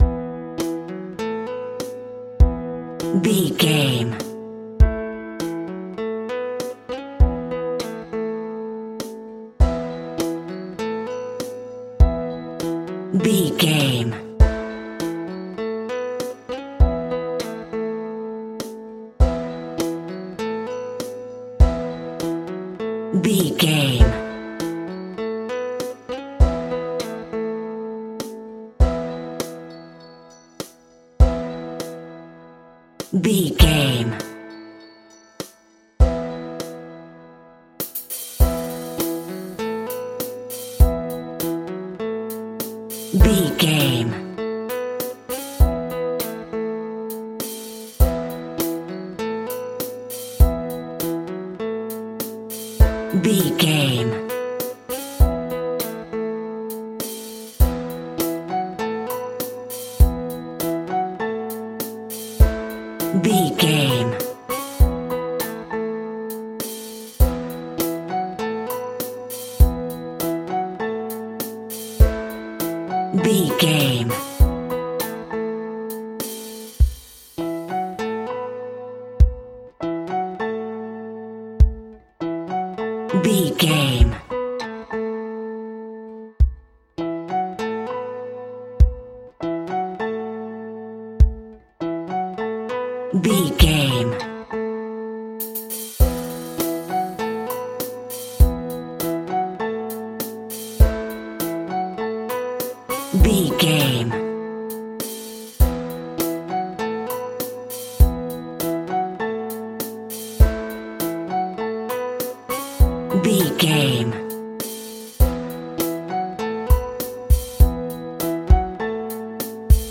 Ionian/Major
sitar
bongos